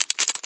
PixelPerfectionCE/assets/minecraft/sounds/mob/spider/step2.ogg at mc116